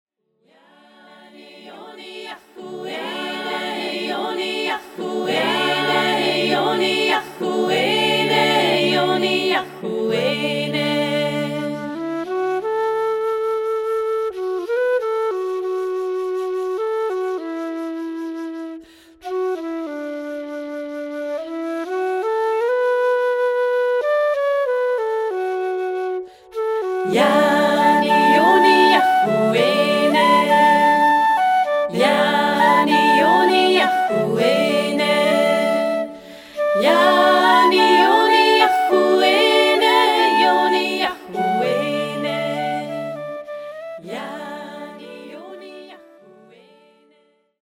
Geburtslied
99 bpm in D